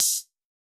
UHH_ElectroHatD_Hit-10.wav